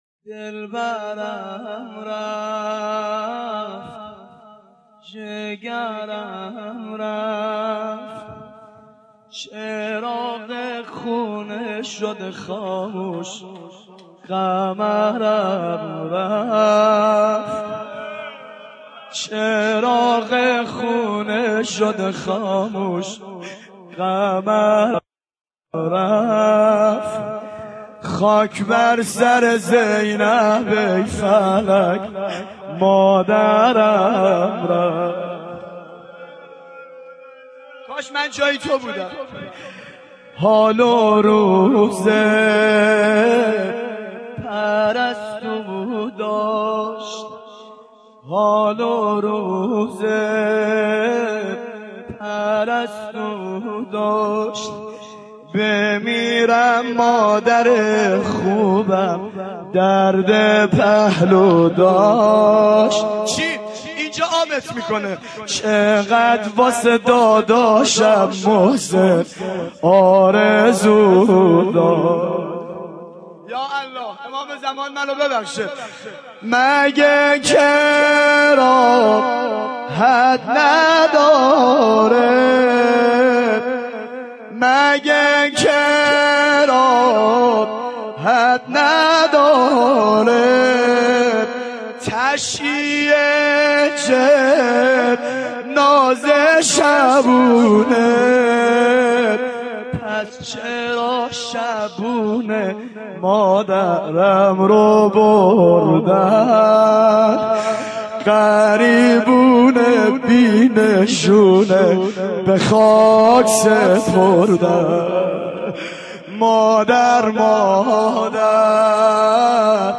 دانلود مداحی خورشید خانه ام - دانلود ریمیکس و آهنگ جدید
زبانحال حضرت زینب کبری (س) در شهادت مادر